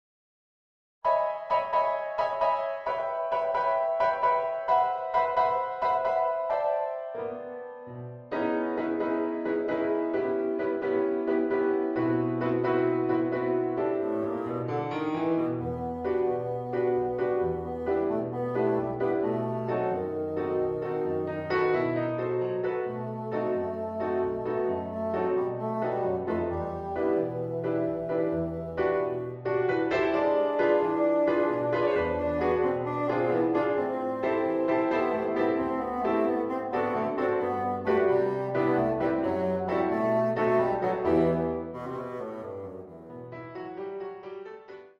bassoon, piano
(Audio generated by Sibelius)